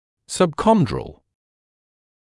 [sʌb’kɔndrəl][саб’кондрэл]субхондральный